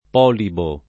[ p 0 libo ]